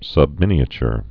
(sŭb-mĭnē-ə-chr, -chər)